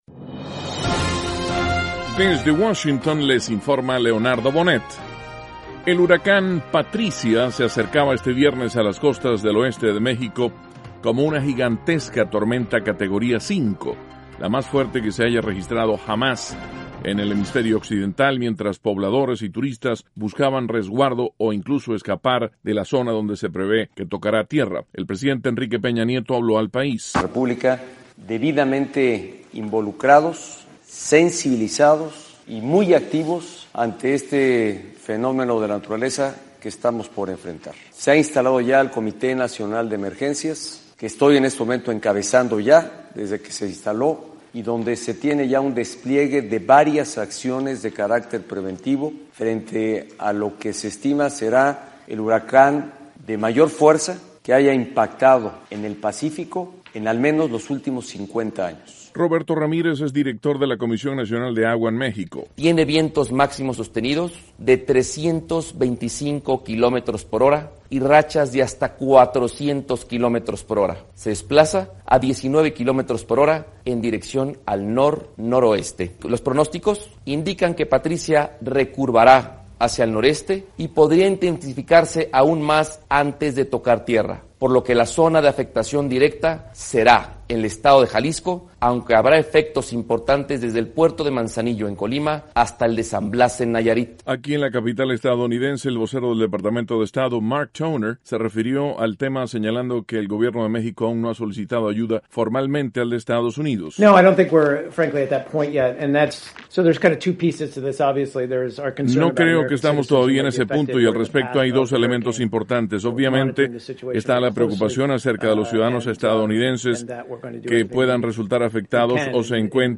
El huracán Patricia, el más fuerte registrado en el hemisferio occidental, tocará tierra en las próximas horas en México. (Sonidos: Presidente Peña Nieto- Director de Comisión Nacional de Agua, Roberto Ramirez y Vocero del Departamento de Estado, Mark Toner).